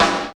47.03 SNR.wav